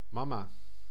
Ääntäminen
IPA: /ˈmɑmaː/